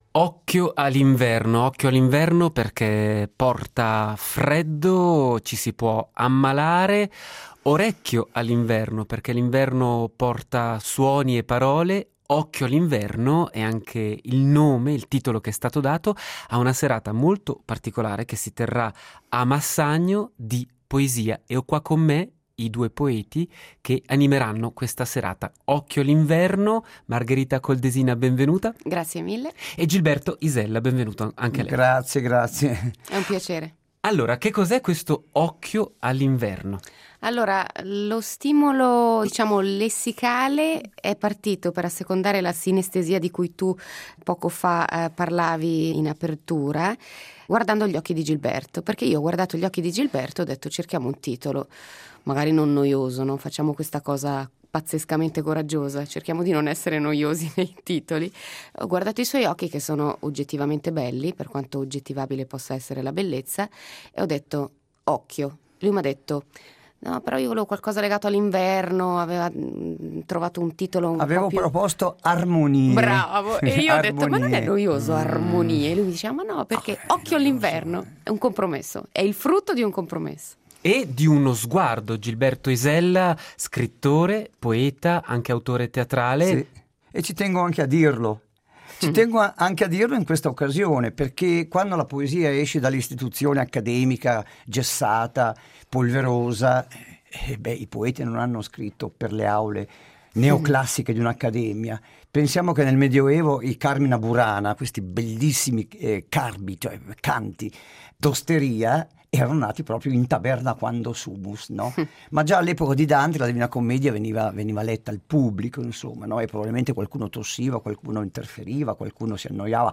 Poesia e musica in un incontro live